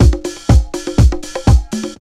C6HOUSE123.wav